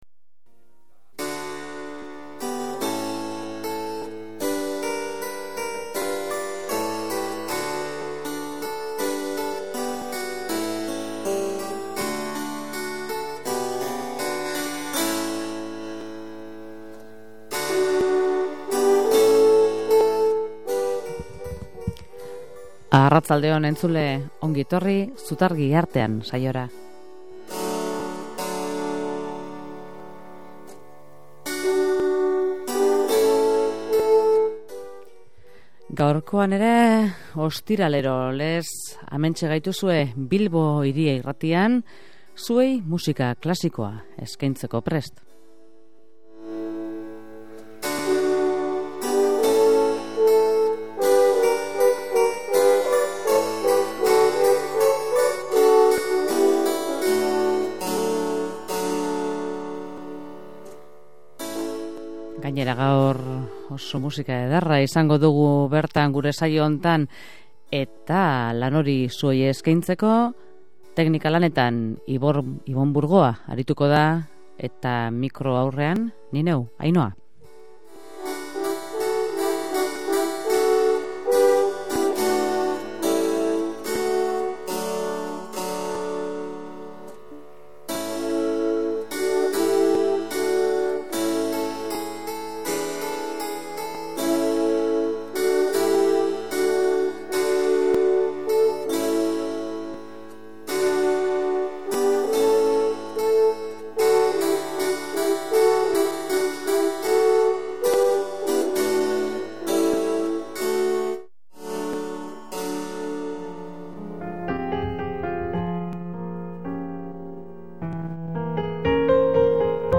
jazz laukotea
bi haur abesbatza